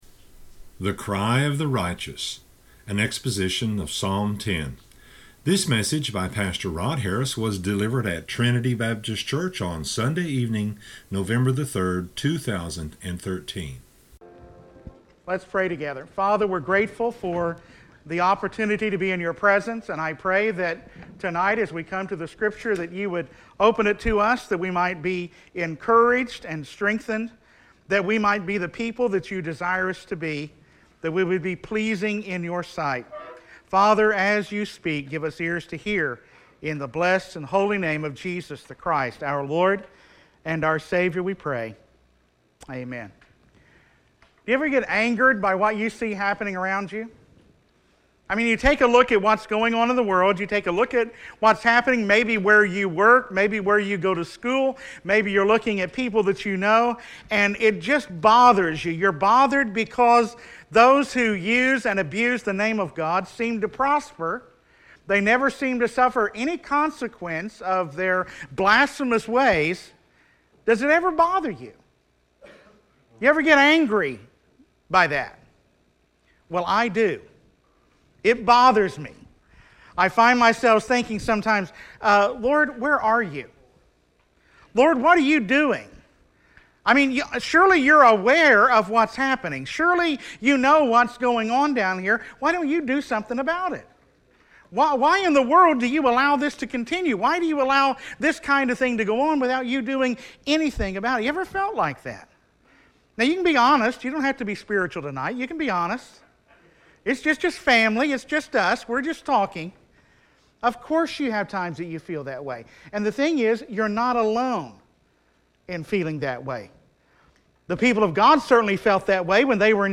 An exposition of Psalm 10:1-18.